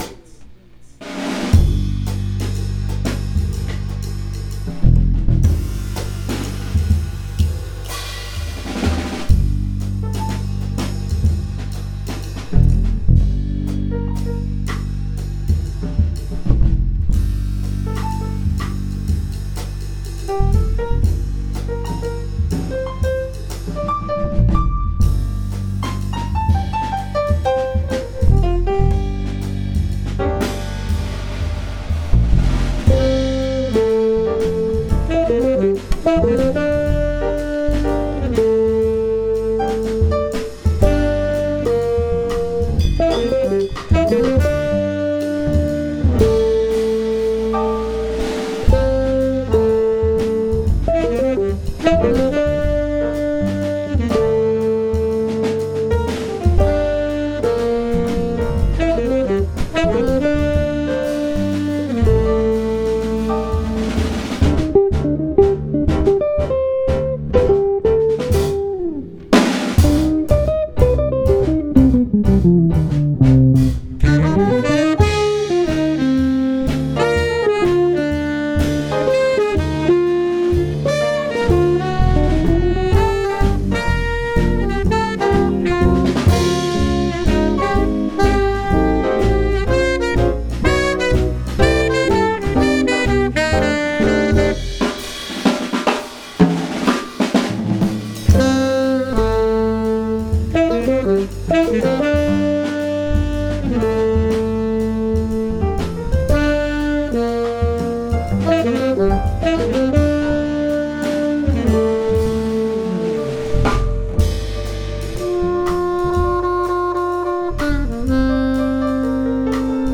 Piano
Bass
Guitar
Alto Sax
Tenor Sax
Drums